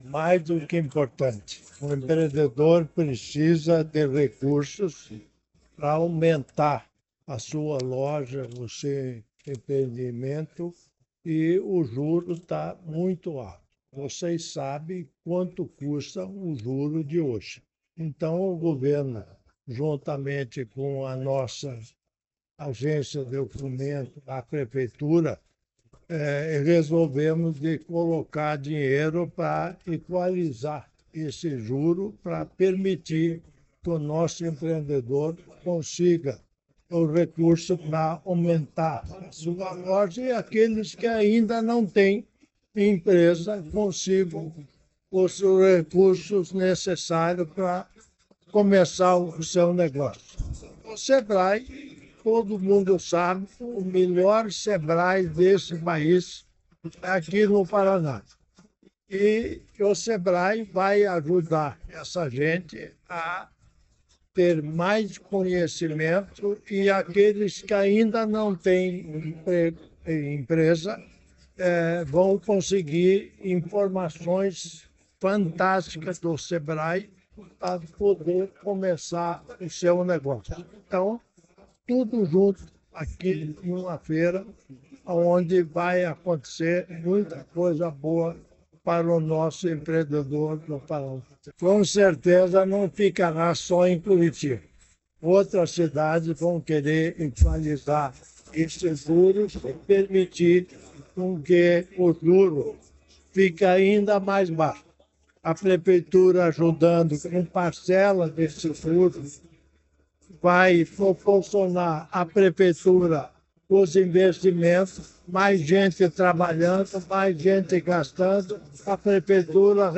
Sonora do vice-governador Darci Piana sobre a redução das taxas de juros e acordo com a Prefeitura de Curitiba para incentivar microcrédito